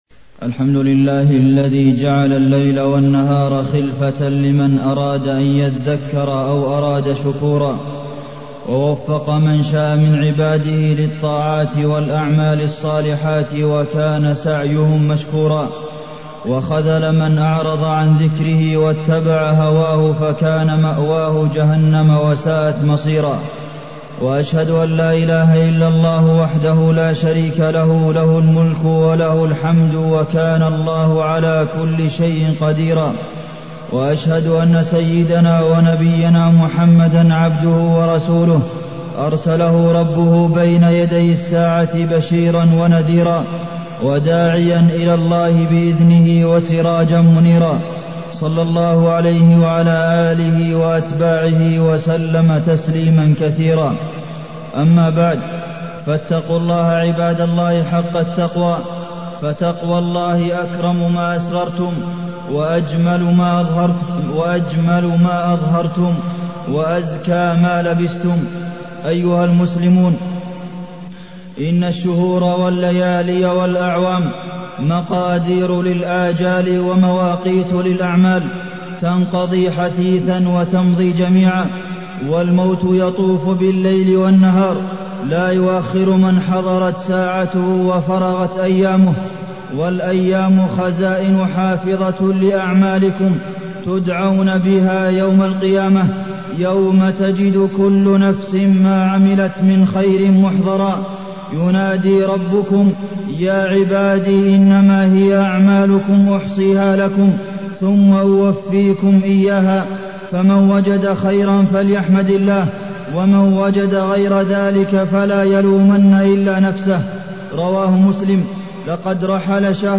تاريخ النشر ٣٠ رمضان ١٤٢٠ هـ المكان: المسجد النبوي الشيخ: فضيلة الشيخ د. عبدالمحسن بن محمد القاسم فضيلة الشيخ د. عبدالمحسن بن محمد القاسم المداومة على الأعمال الصالحة بعد رمضان